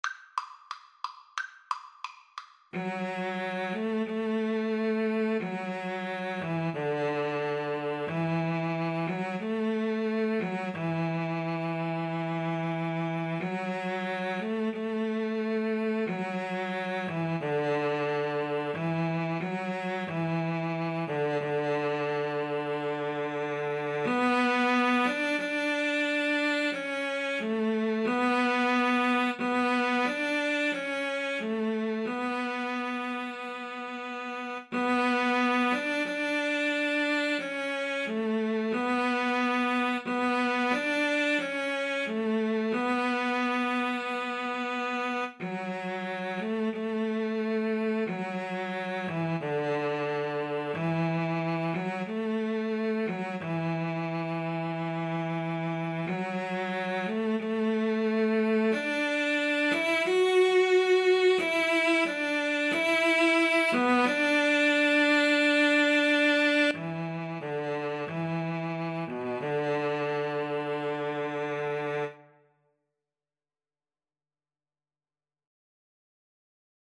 Free Sheet music for Cello Duet
D major (Sounding Pitch) (View more D major Music for Cello Duet )
=180 Largo